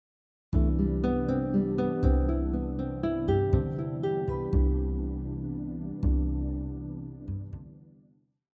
This first example uses an ascending four note pattern using only the C Major 7 chord tones: C, E, G, and B.
Major-7-arpeggio-example-1.mp3